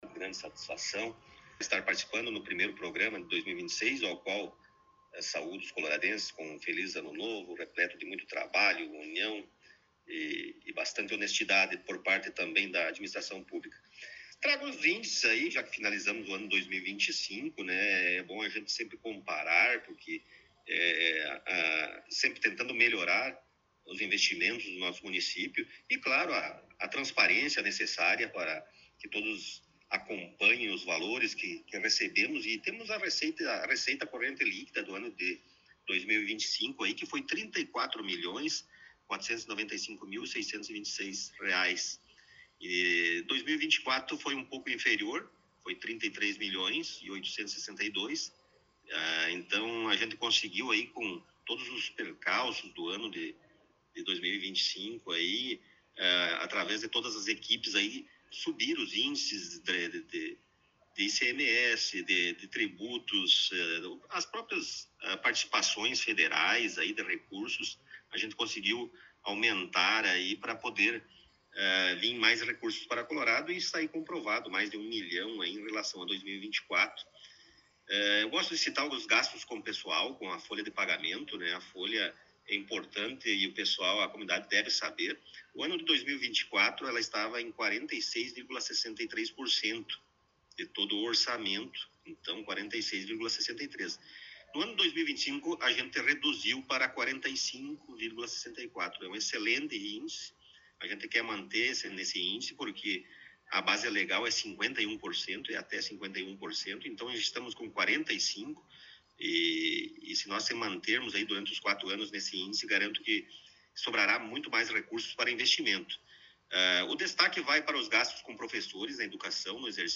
No início deste novo ano, tivemos a oportunidade de entrevistar o prefeito Rodrigo Sartori em seu gabinete na Prefeitura Municipal.